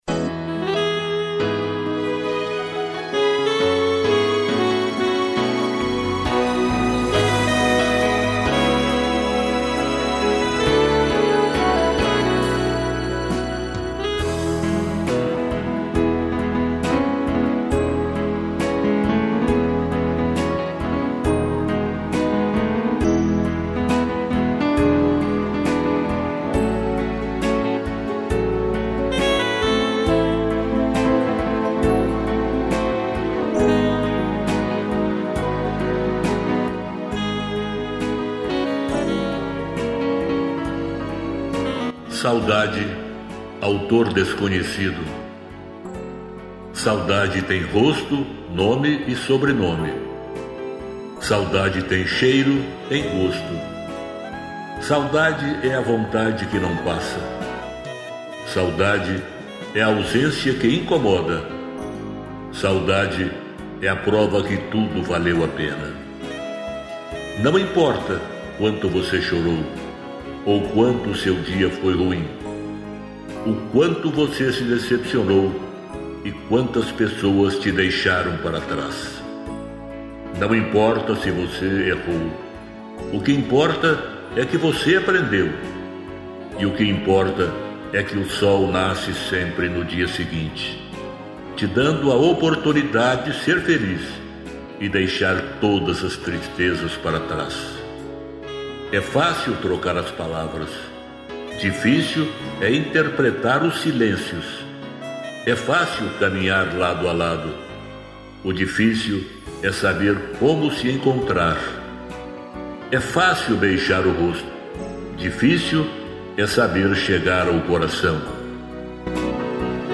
sax, strings e violão